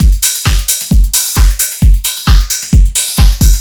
ELECTRO 12-L.wav